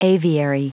Prononciation du mot aviary en anglais (fichier audio)
Prononciation du mot : aviary